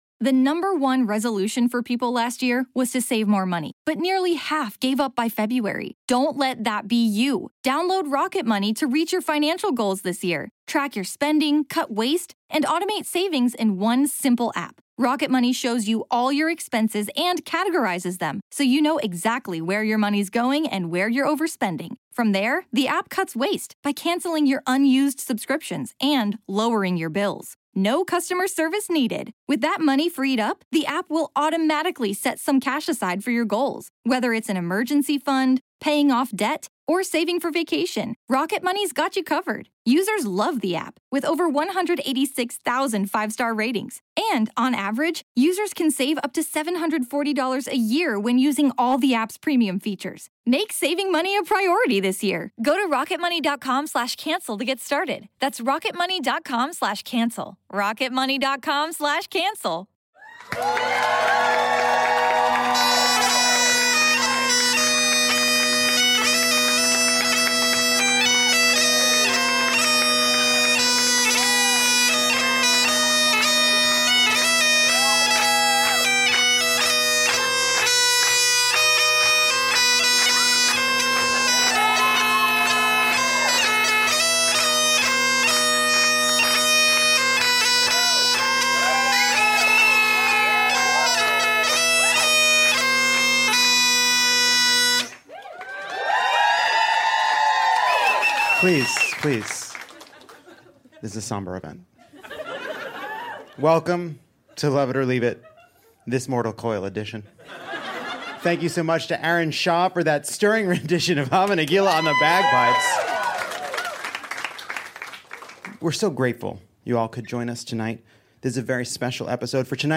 Cover the mirrors and don your kippah, it’s time to sit shiva for Lilibit. Grab some nosh and swap stories of Her Highness with Senator Bernie Sanders (James Adomian). Enjoy the dulcet tones of the very real bagpiper playing Hava Nagila as Brandon Kyle Goodman and Sam Pancake pay homage to Her Majesty’s famously sharp sense of humor with some Gay News.